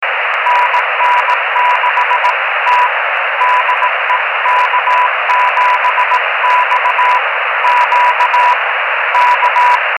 recibidos a 58.000 kms y una elevación de 8º, por medio unos 25 mts de coaxial, que se encargan de atenuar algo las señales.